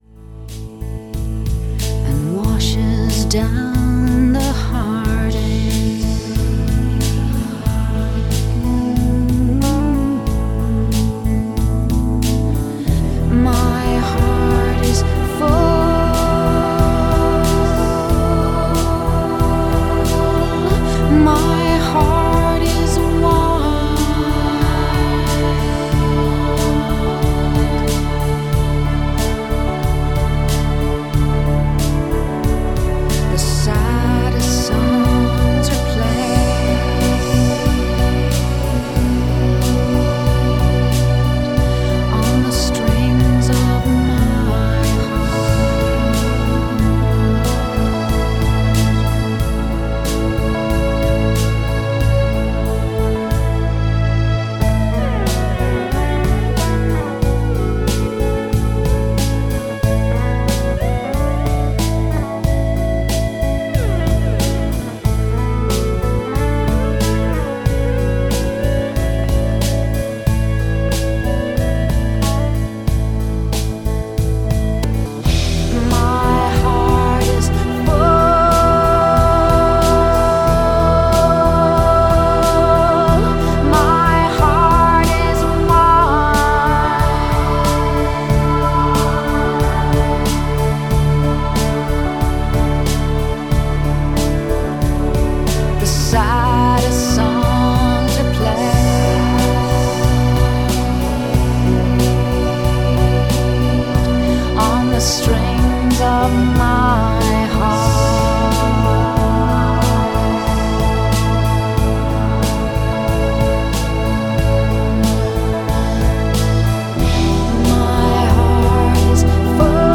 Ce disque est pur produit de musique électronique.